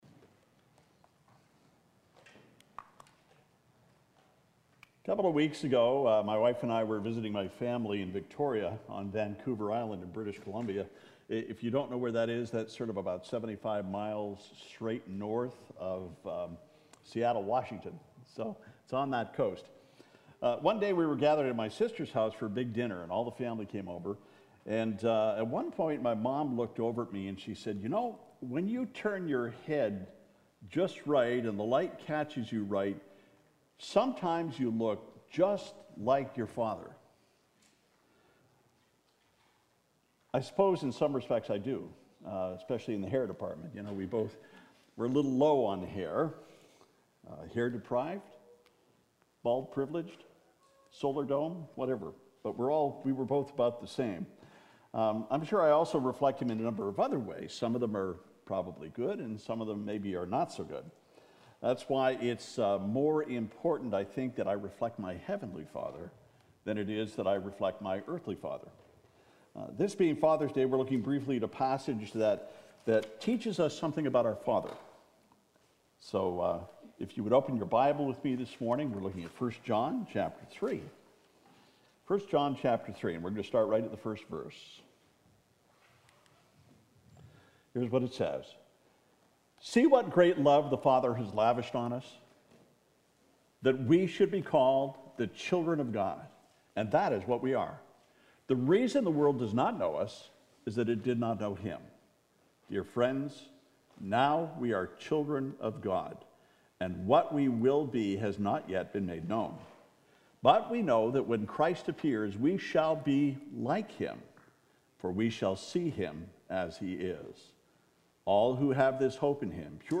Father’s Day: “Our Father’s Love” 1 John 3:1 « FABIC Sermons